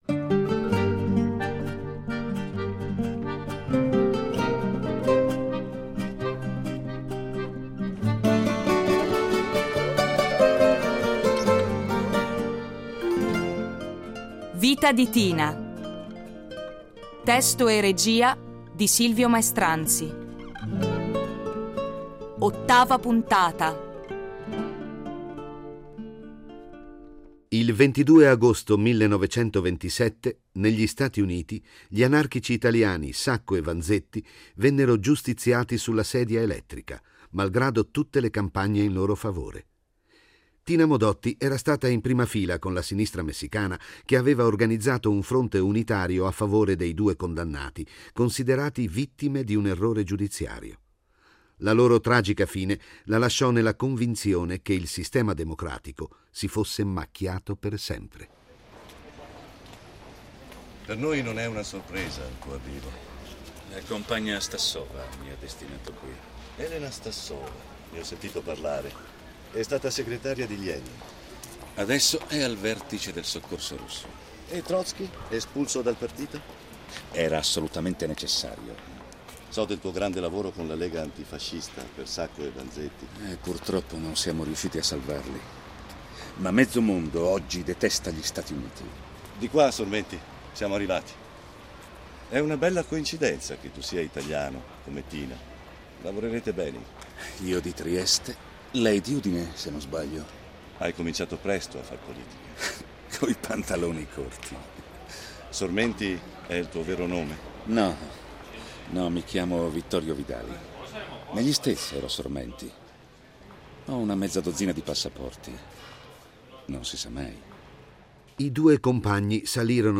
Originale radiofonico in 18 puntate